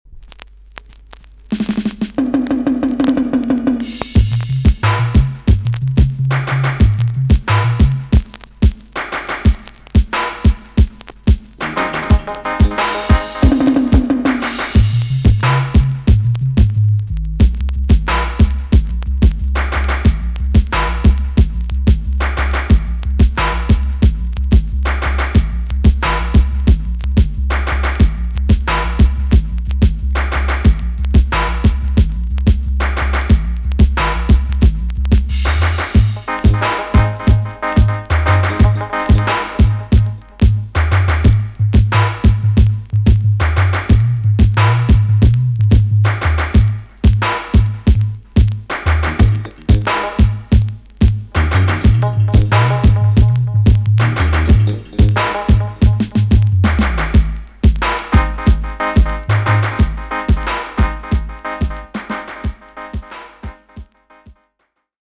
TOP >80'S 90'S DANCEHALL
B.SIDE Version
EX-~VG+ 少し軽いチリノイズが入ります。